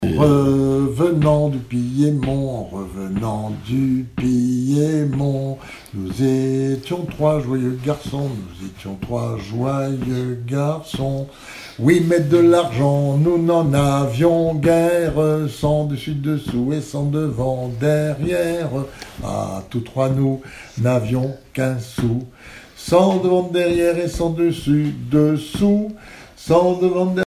Genre laisse
Catégorie Pièce musicale inédite